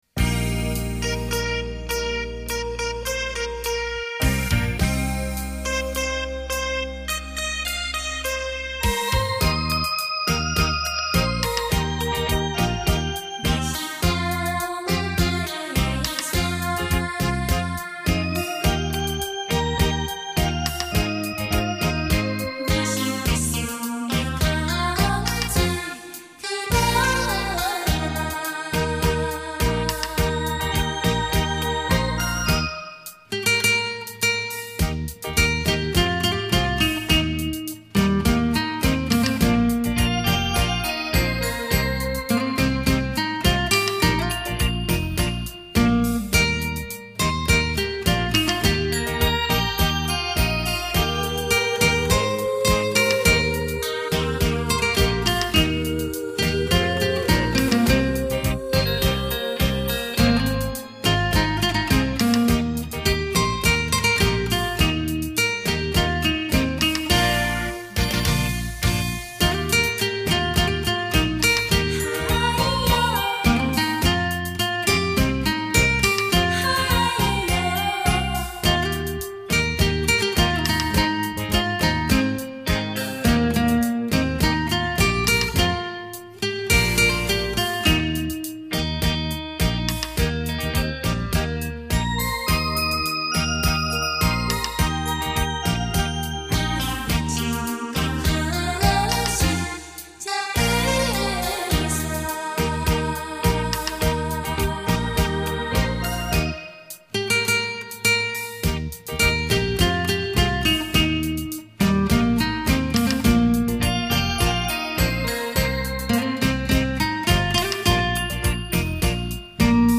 久盛不衰的台语金曲，超立体电子琴完美演绎；